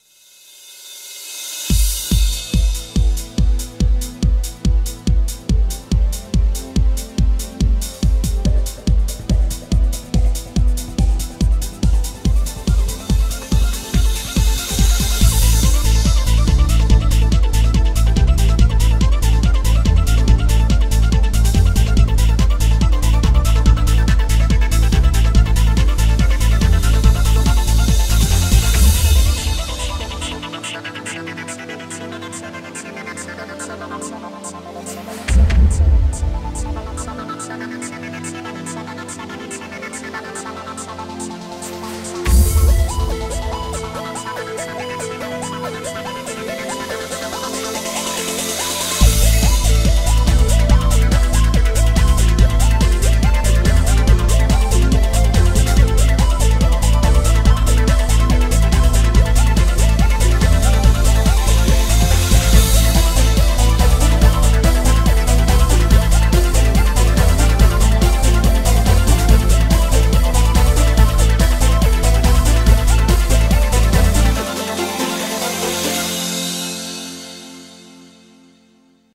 BPM142
Audio QualityMusic Cut